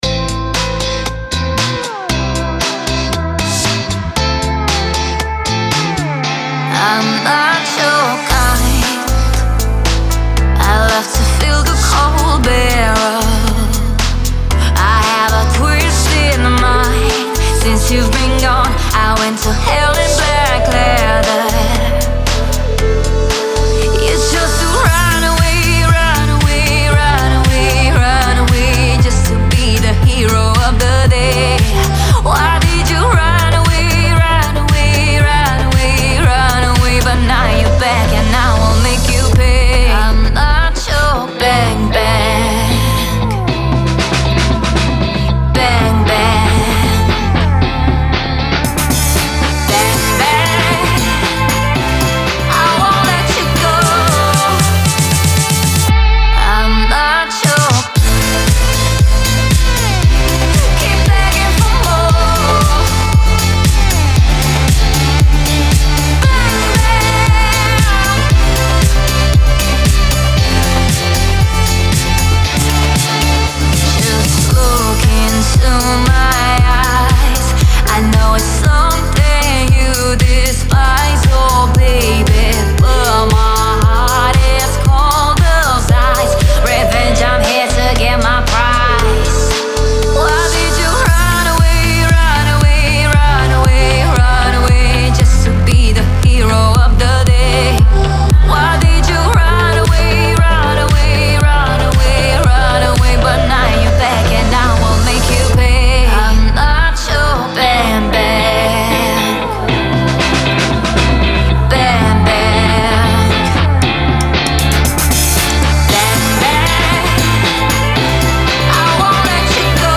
это мощная композиция в жанре хип-хоп